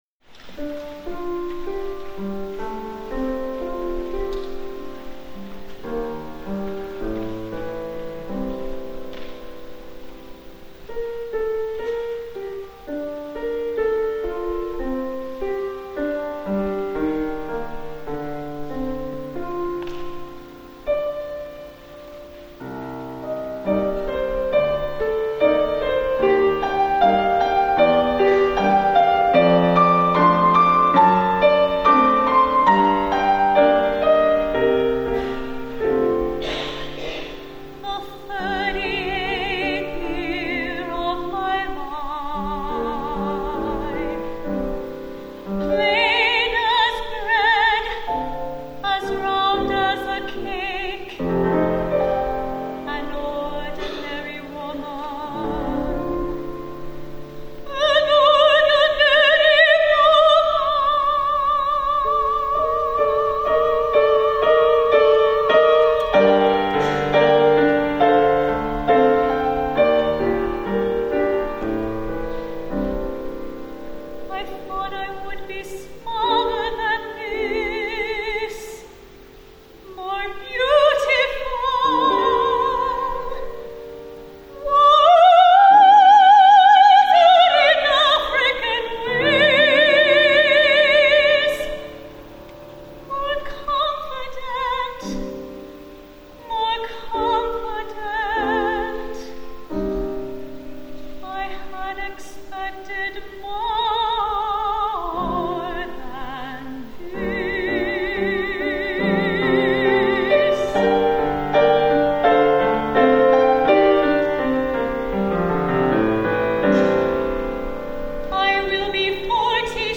for Soprano and Piano (1997)